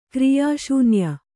♪ kriyā śunya